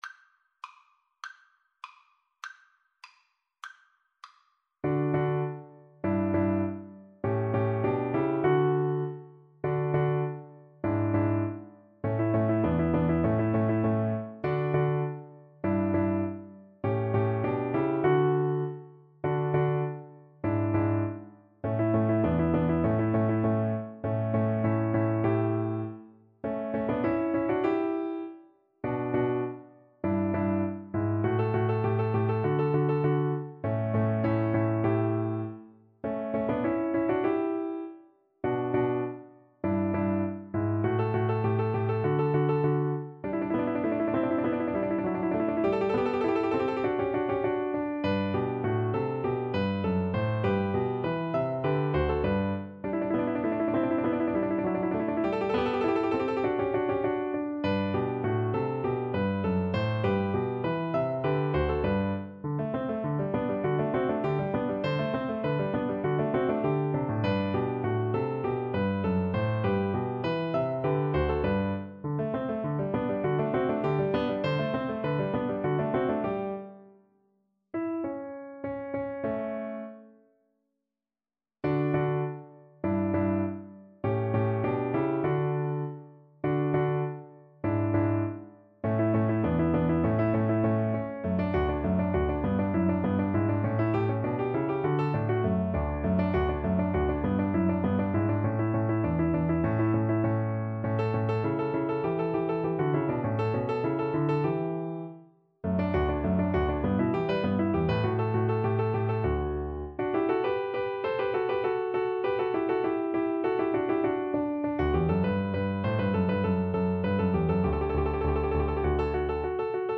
Play (or use space bar on your keyboard) Pause Music Playalong - Piano Accompaniment Playalong Band Accompaniment not yet available transpose reset tempo print settings full screen
Violin
This piece is uniformly light and playful in character to reflect his interest in musical clock in the nineteenth century.
D major (Sounding Pitch) (View more D major Music for Violin )
2/4 (View more 2/4 Music)
D5-A6
Classical (View more Classical Violin Music)